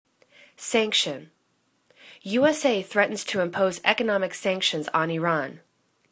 sanc.tion     /'sangshən/    n